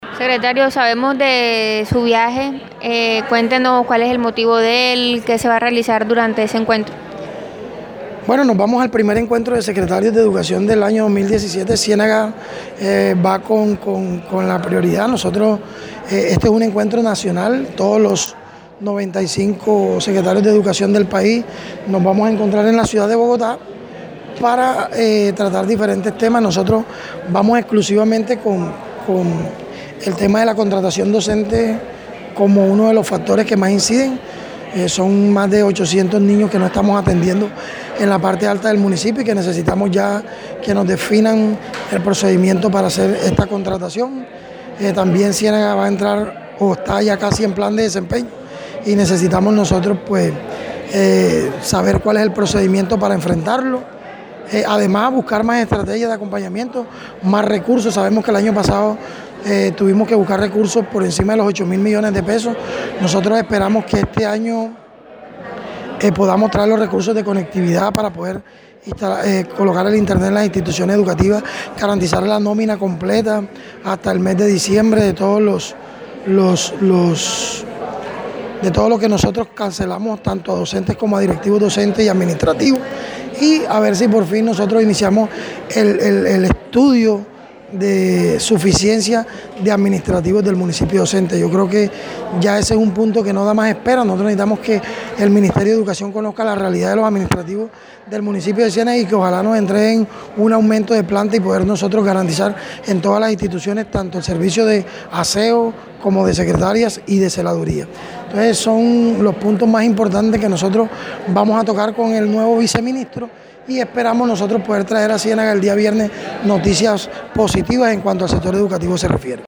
Declaraciones del secretario de Educación de Ciénaga